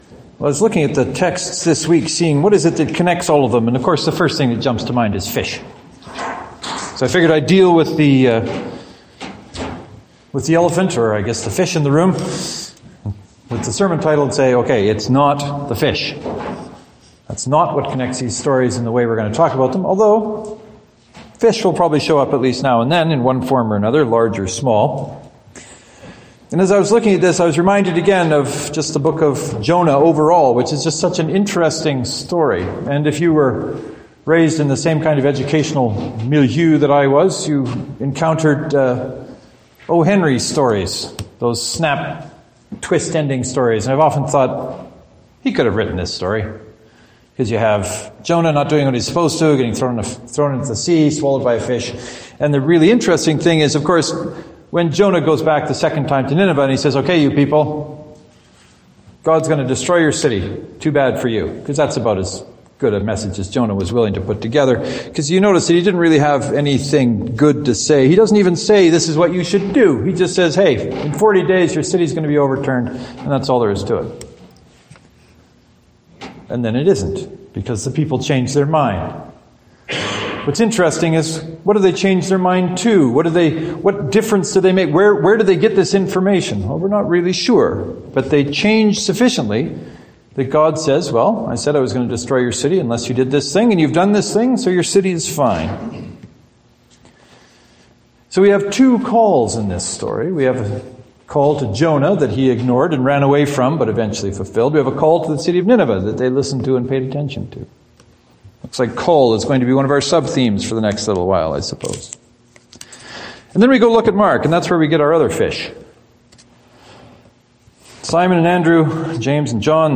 Are we called into uncertainty? Give one or both of the sermons a listen and let me know what you think.